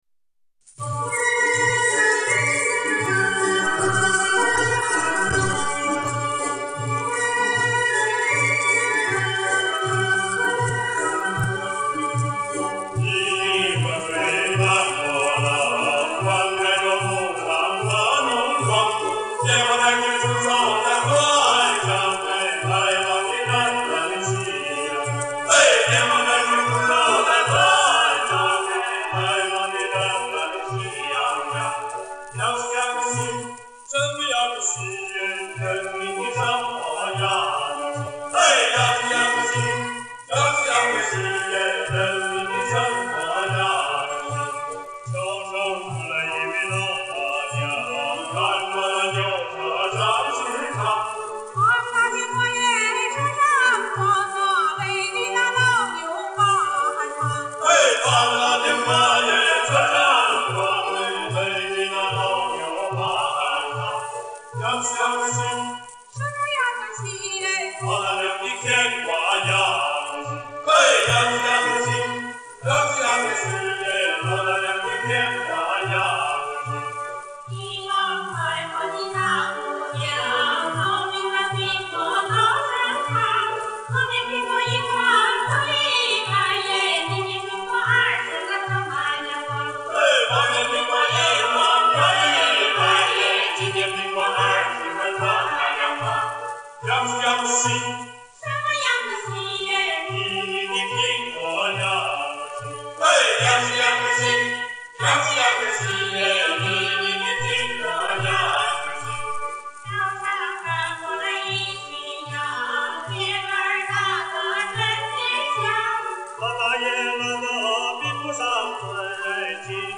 吐鲁番维吾尔族民歌
民族乐队伴奏